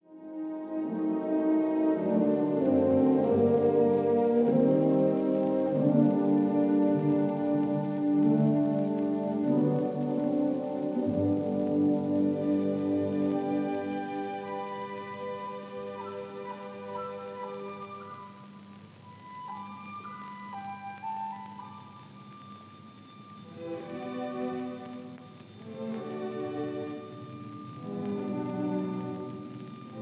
Allegro con brio